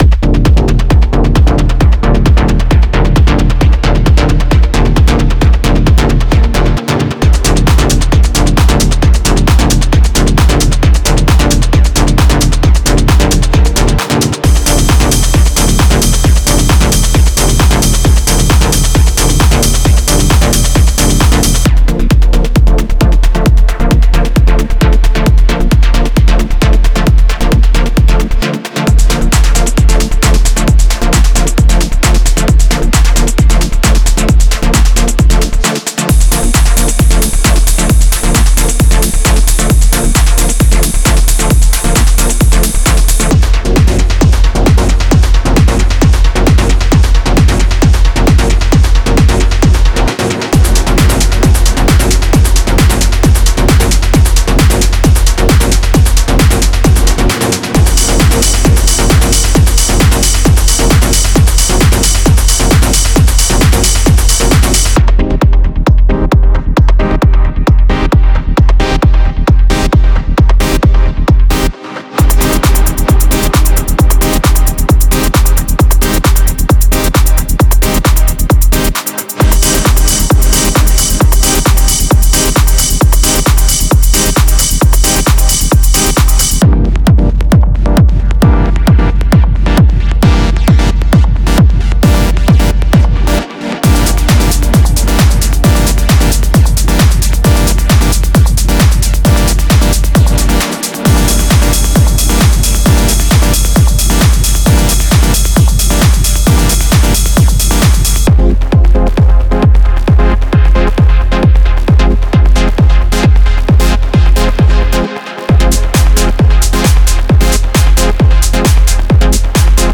Genre:Dub Techno
デモサウンドはコチラ↓
95 Drum loops (Full, Kick, Clap, Hihat, Perc, Ride)